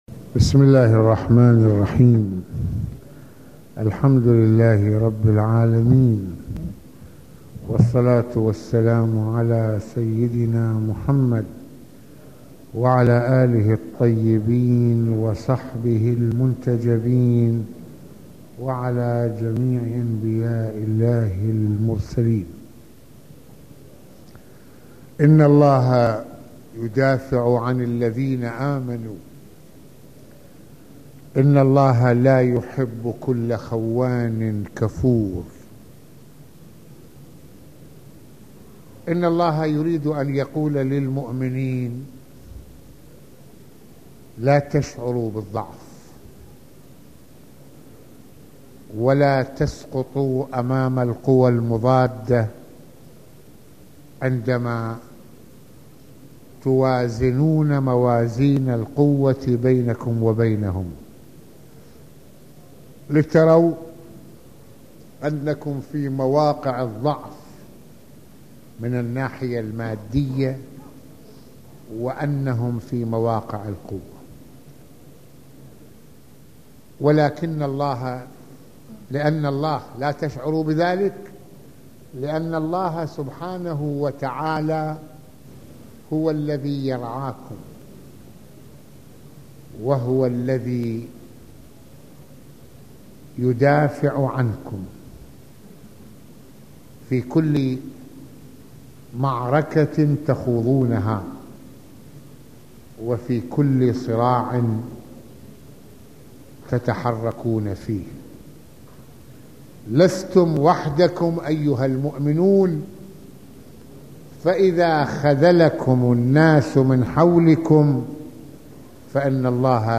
- يتحدث المرجع السيد فضل الله (رض) في هذه المحاضرة شرحه للآية "38" والآية "41" من سورة الحج المباركة عن ضرورة عدم الشعور بالضعف والسقوط أمام التحديات وأنّ الله ينصر المؤمنين في ساحات الصراع فيما المطلوب منهم أن يعدوا الوسائل للدفاع بها عن النفس والدين والحياة فالله لا بد أن يذل الخوانين الكفّار بما يحملون من عناصر الضرر ويتناول أيضاً الدروس المستفادة من تجربة النبي(ص) لجهة أهمية دراسة ظروف الساحة والتحرك على أساسها حفاظاً على المصلحة ، ويعرض للإذن الإلهي للمؤمنين بالقتال والدفاع عن أنفهسم وعقيدتهم وأرضهم ، وأن يستغلوا عناصر القوة لديهم وألاّ يستكينوا ، وينشروا كلمة التوحيد...